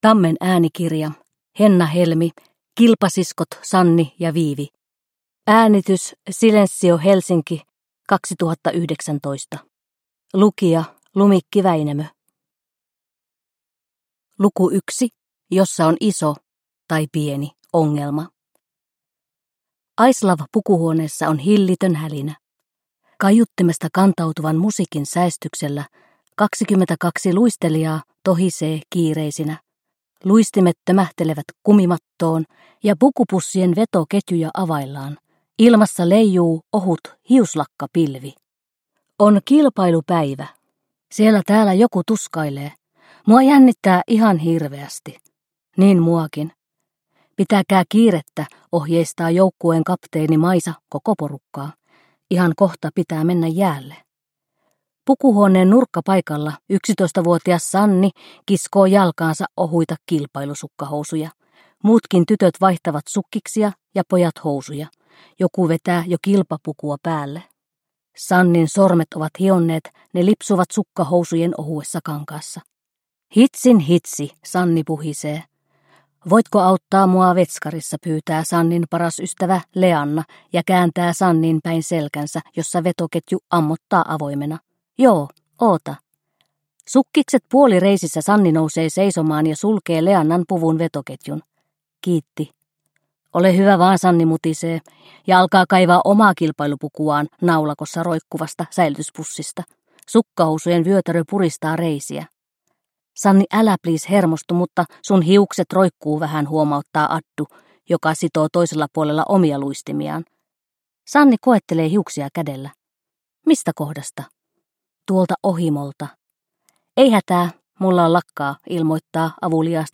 Kilpasiskot Sanni ja Viivi – Ljudbok